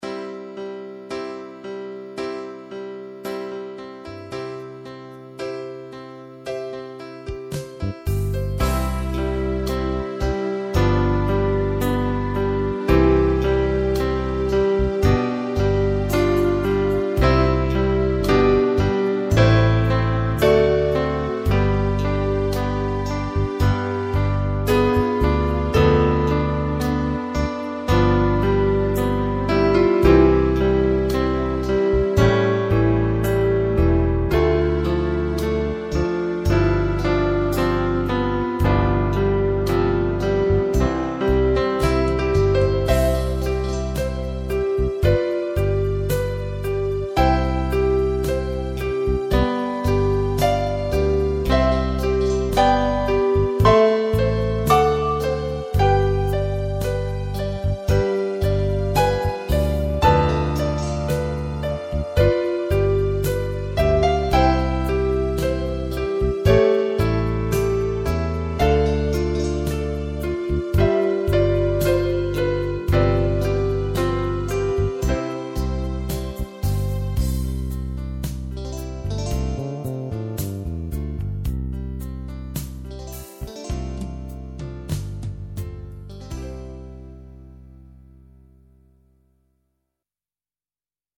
Keyboard und Synthesizer-Klassik
Keyboard un Klavier